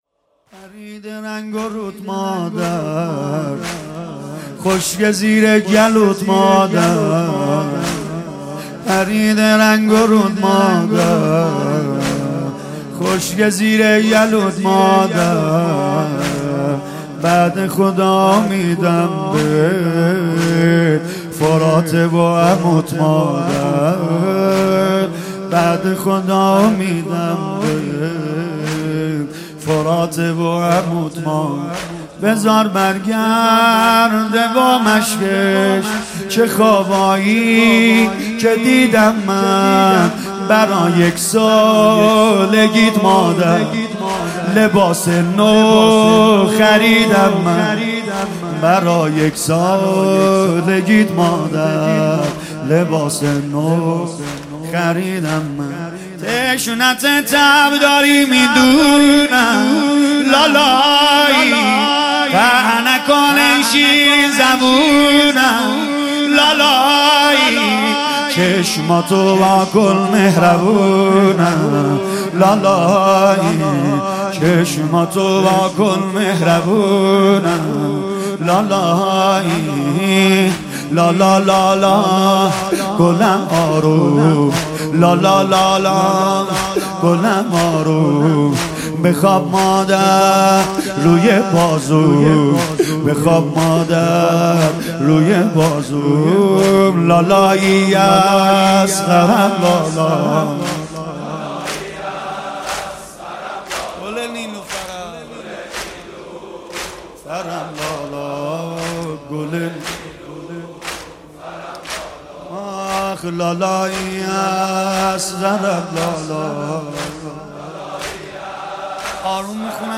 شب هفتم